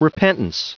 Prononciation du mot repentance en anglais (fichier audio)
Prononciation du mot : repentance